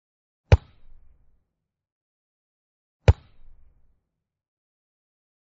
Âm thanh Đá bóng, đá vào quả bóng…
Thể loại: Tiếng động
Description: Âm thanh đá bóng, tiếng sút bóng, tiếng chạm bóng, tiếng đập bóng... hiệu ứng âm thanh và chỉnh sửa video giúp tăng cảm giác thực tế cho người xem.
am-thanh-da-bong-da-vao-qua-bong-www_tiengdong_com.mp3